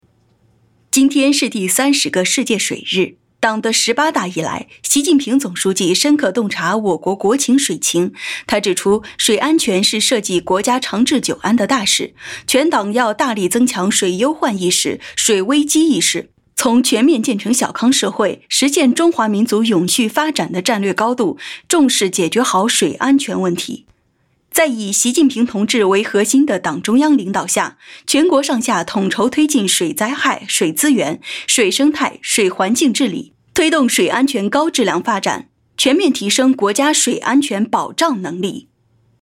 新闻专题